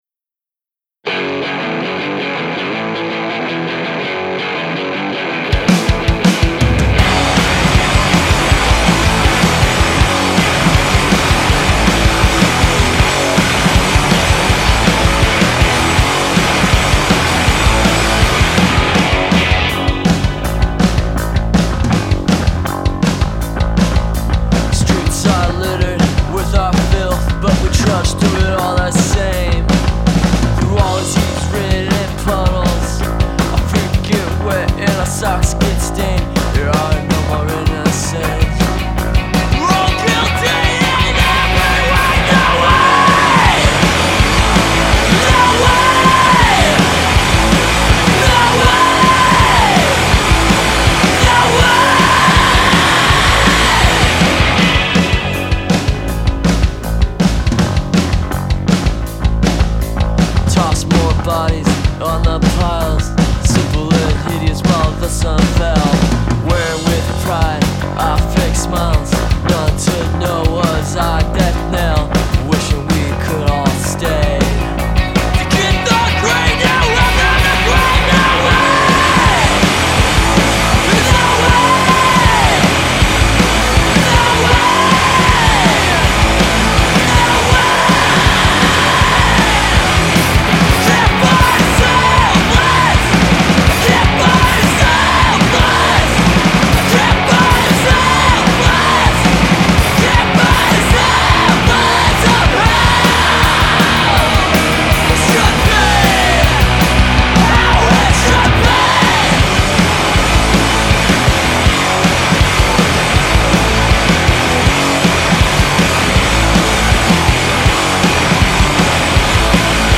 lead vocalist